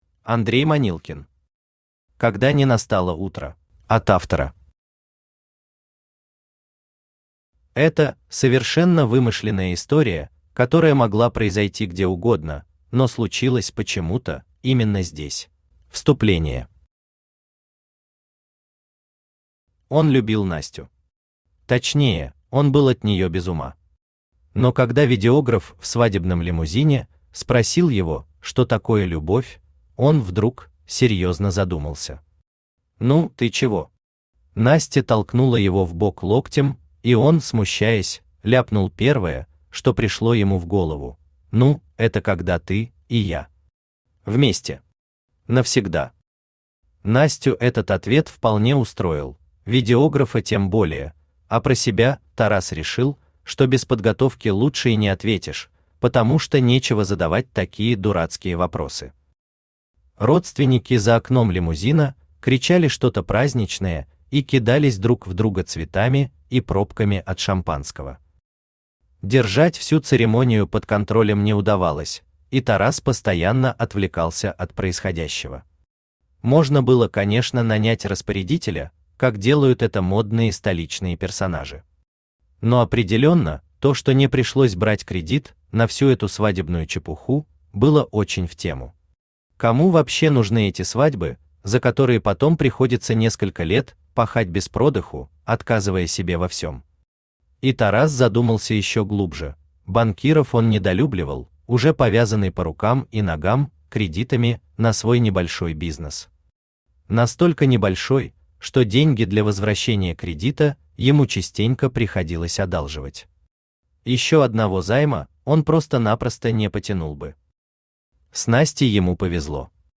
Аудиокнига Когда не настало утро | Библиотека аудиокниг
Aудиокнига Когда не настало утро Автор Андрей Манилкин Читает аудиокнигу Авточтец ЛитРес.